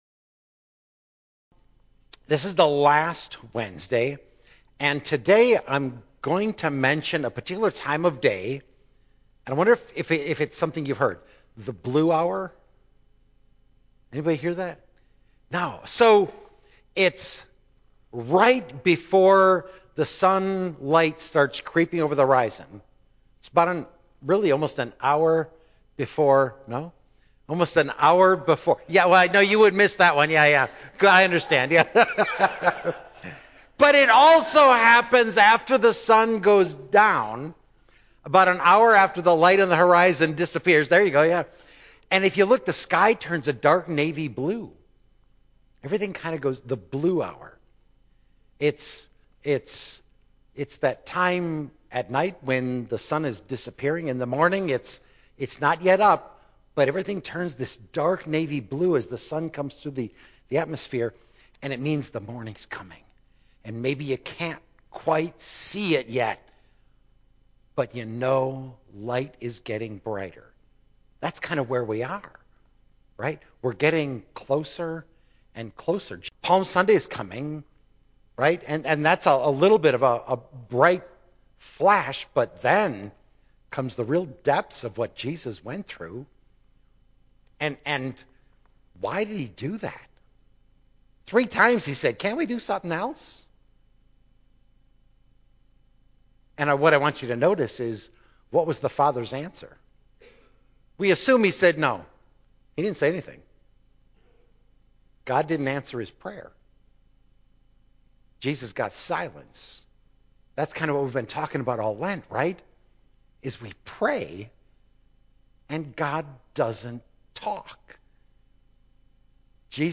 Sermon Audio recording